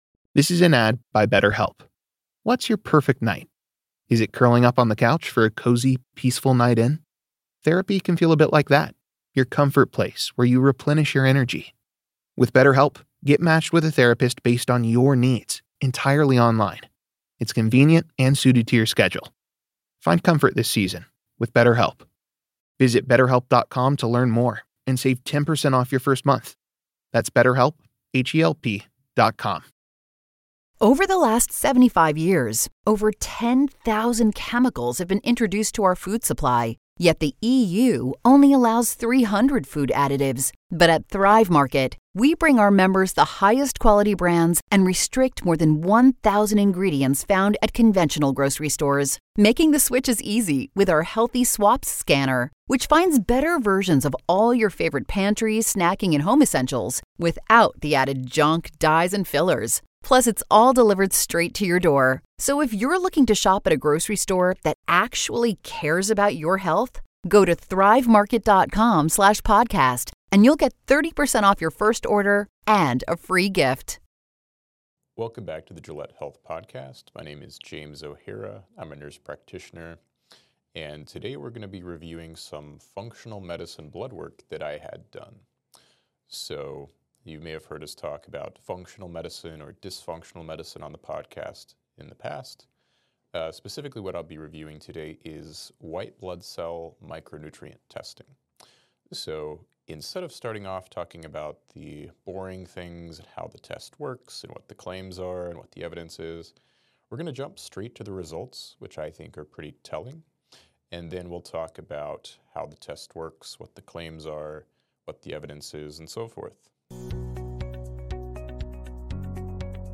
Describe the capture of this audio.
at the Insight Meditation Center in Redwood City, CA